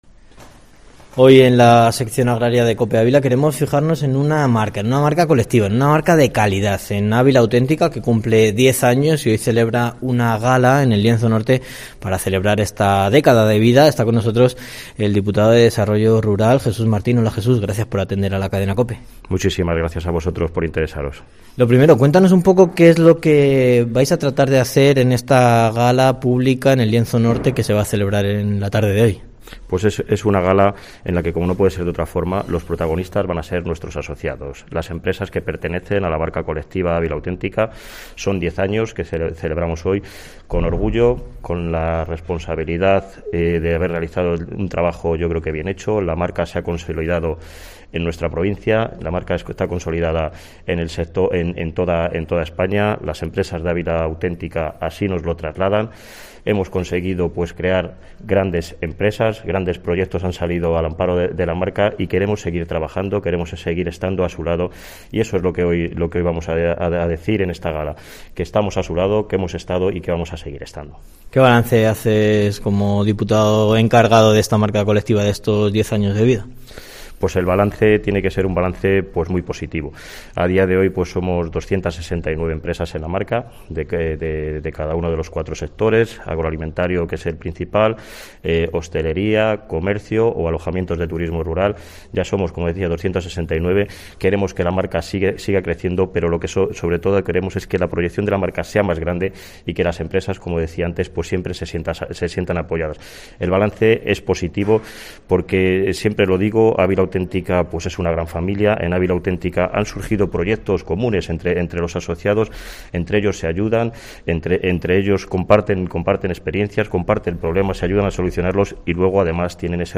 ENTREVISTA
Entrevista en Herrera en COPE Ávila con el diputado de desarrollo Rural, Jesús Martín sobre el X Aniversario de Ávila Autentica